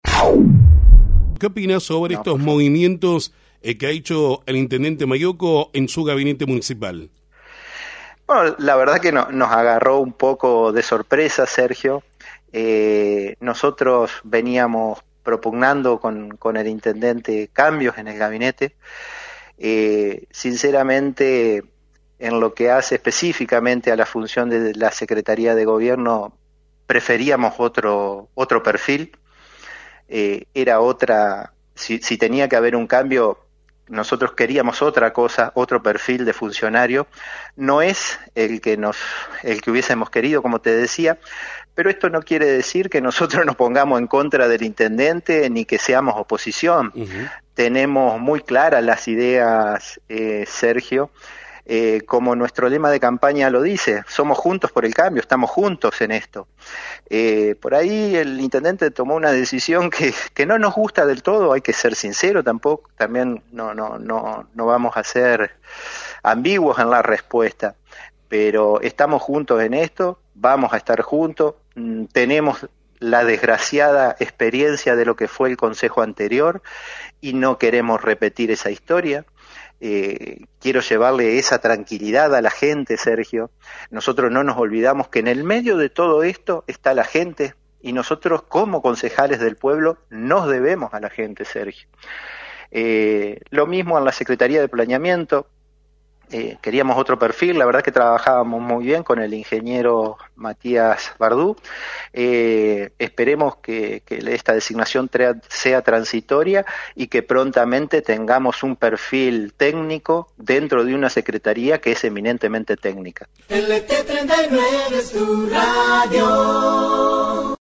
El presidente del bloque de Juntos por el Cambio, Franco Brasessco se despachó esta tarde en el programa Radionoticias de FM 90.3 con una clara crítica a los movimientos de gabinete realizadas por el intendente, Domingo Maiocco.